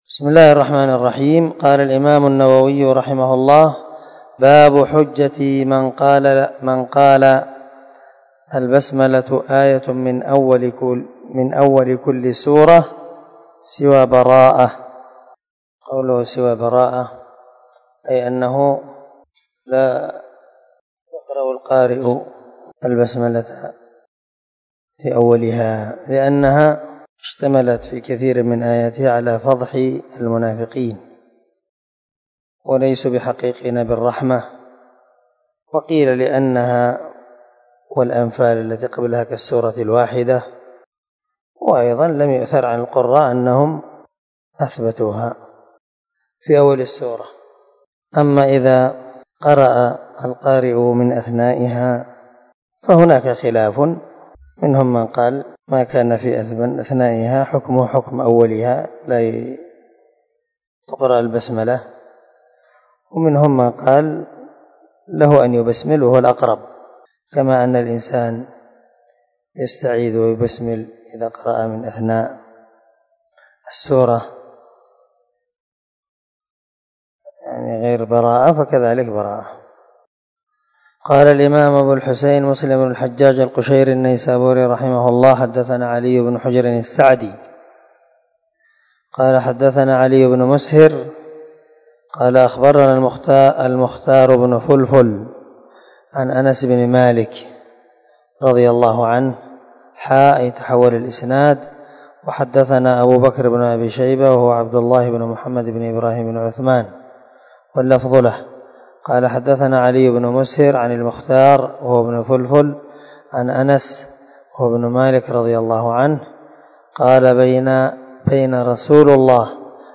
276الدرس 20 من شرح كتاب الصلاة حديث رقم ( 400 ) من صحيح مسلم
دار الحديث- المَحاوِلة- الصبيحة